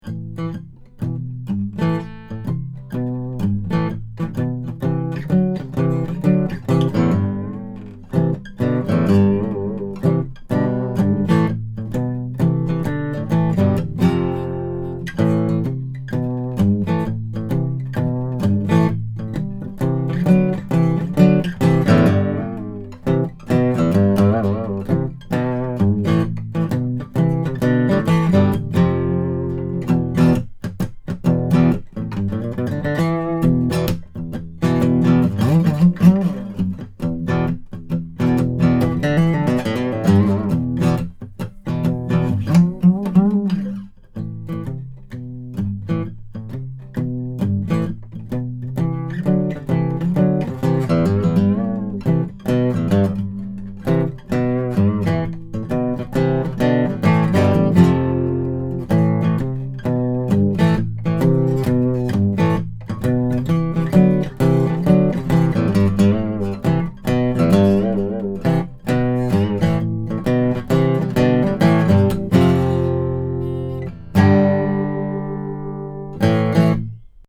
AKG C414 EB
Here are 15 quick, 1-take MP3 sound files showing how the mic sounds through a Presonus ADL 600 preamp into a Rosetta 200 A/D converter. No EQ or effects.
SANTA CRUZ OM/PW AC. GUITAR: